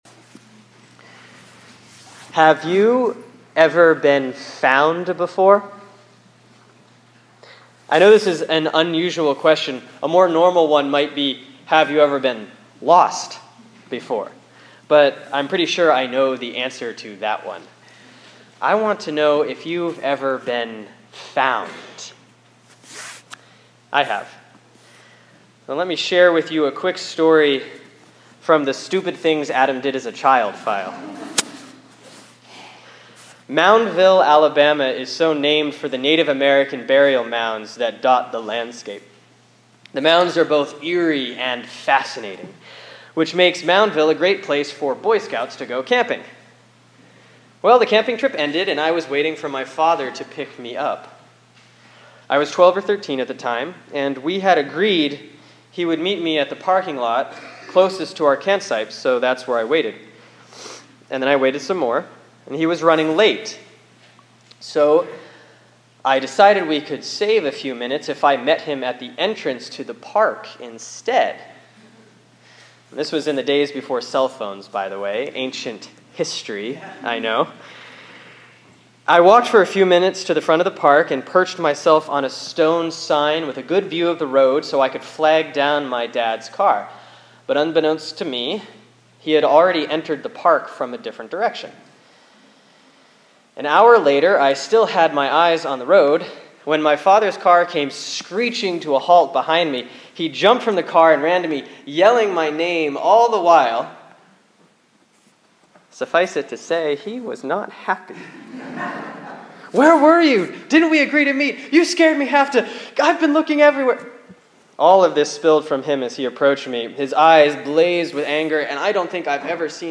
Sermon for Sunday, September 15, 2013 || Proper 19C || Luke 15:1-10 )